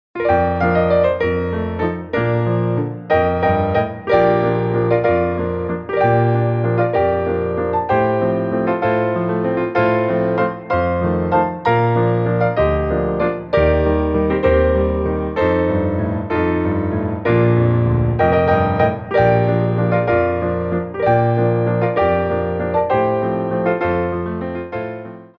3/4 (8x8)